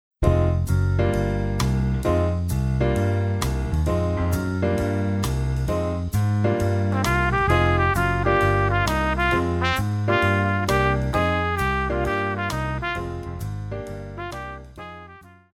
Jazz,Pop
Trumpet
Instrumental
Smooth Jazz,Rock
Only backing